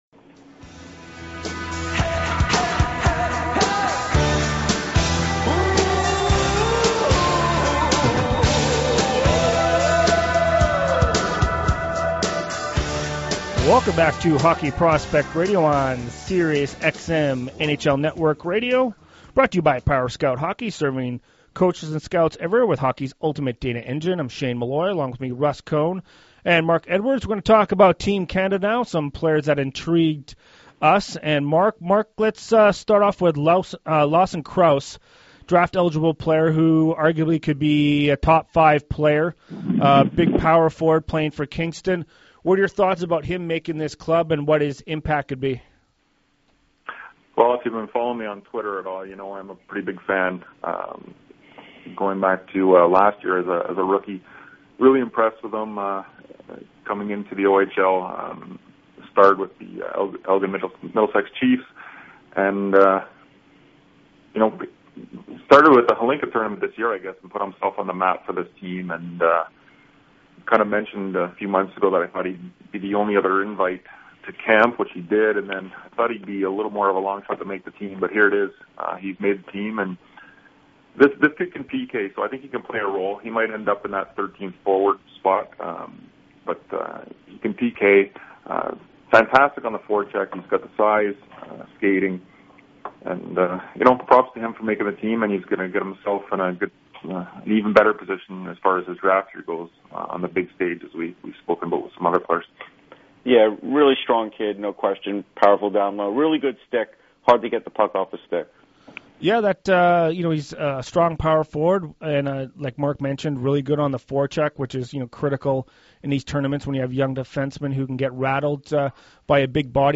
Sirius XM’s Hockey Prospects show did their annual World Jr. Preview show on NHL Network radio.